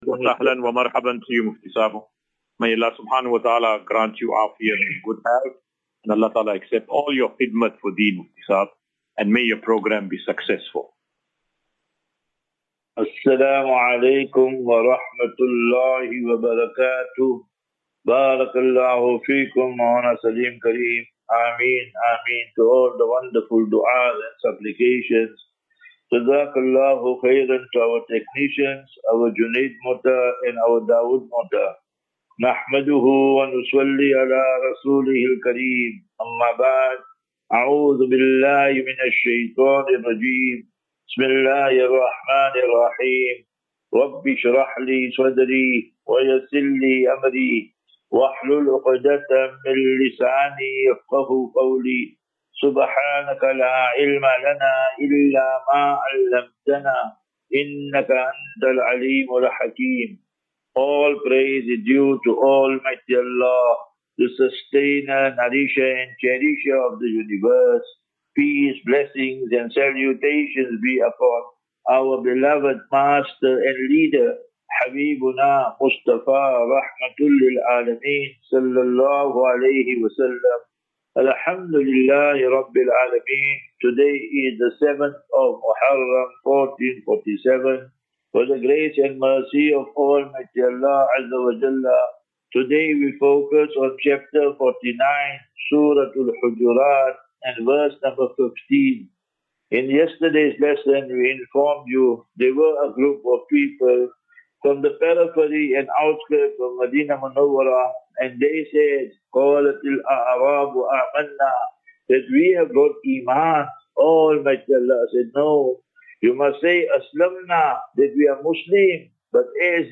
Assafinatu - Illal Jannah. QnA.